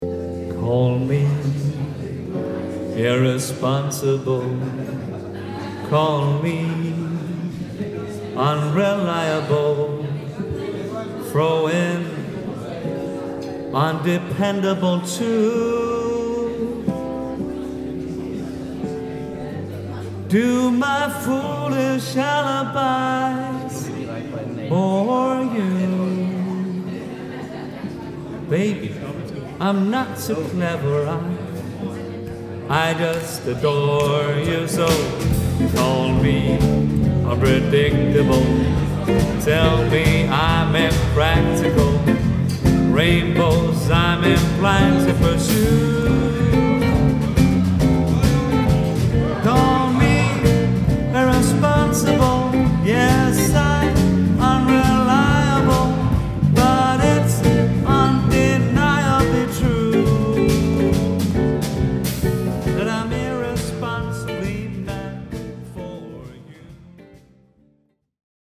Lækker lounge og swing, glad jazz på 123festbands!
• Jazzband